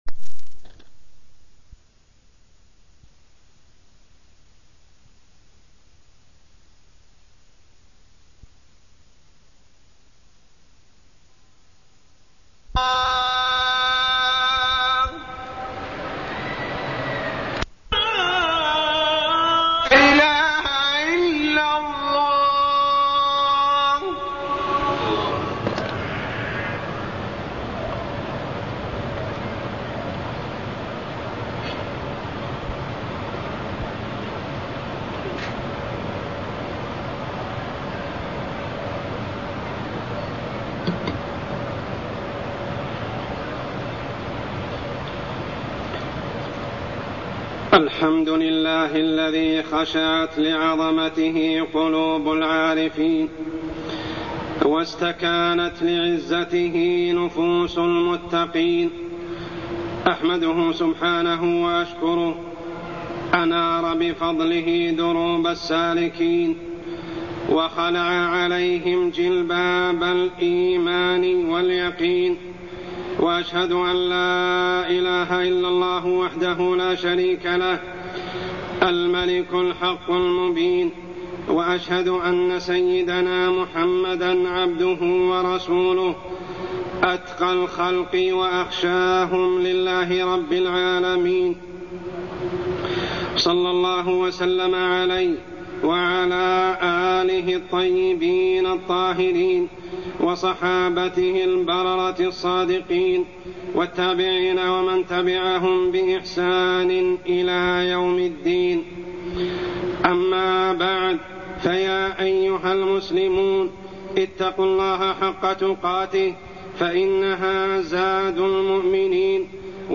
تاريخ النشر ٢٩ صفر ١٤٢١ هـ المكان: المسجد الحرام الشيخ: عمر السبيل عمر السبيل خشية الله سبحانه وتعالى